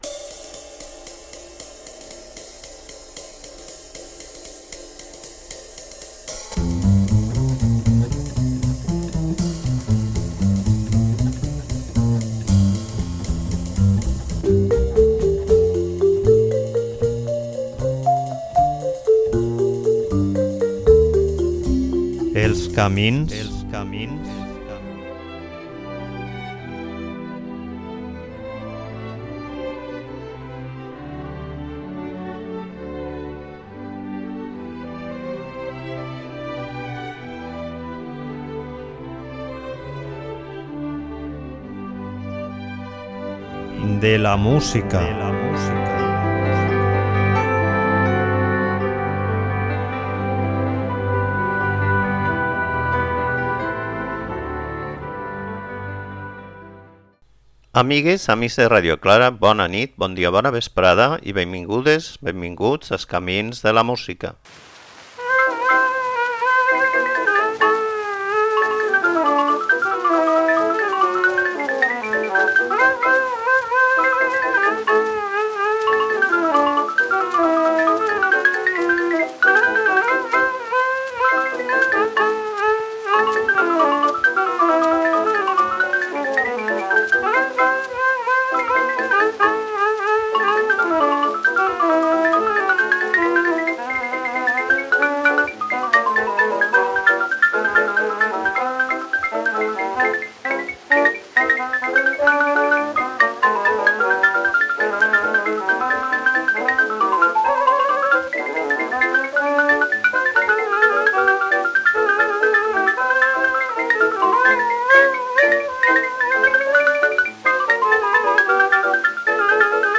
Música lliure de les cadenes dels mercaders-2 Programa 221 Músiques amb llicències Creative Commons, de l'àmbit de la música del jazz i alternatives Envia per correu electrònic BlogThis!